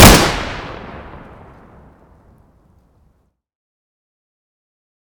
kar98k_shoot_misfire.ogg